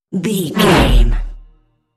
Sci fi electronic whoosh
Sound Effects
Atonal
futuristic
whoosh